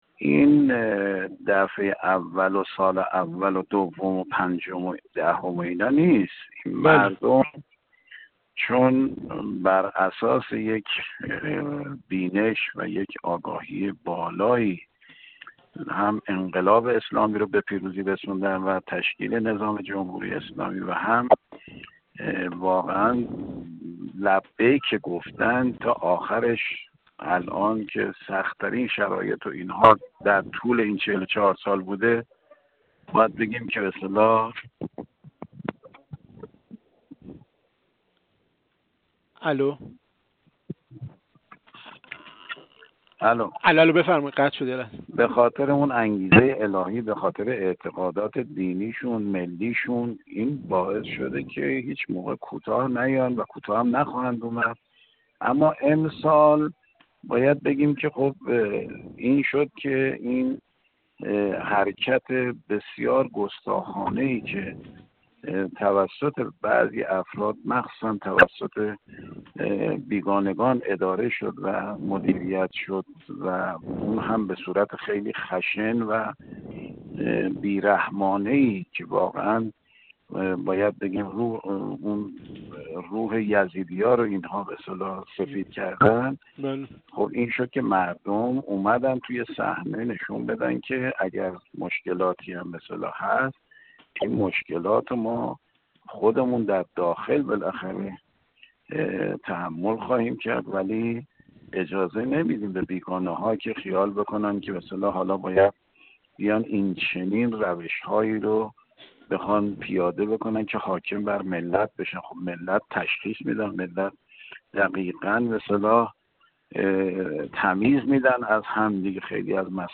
سردار اسماعیل کوثری، عضو کمیسیون فرهنگی مجلس
گفت‌وگو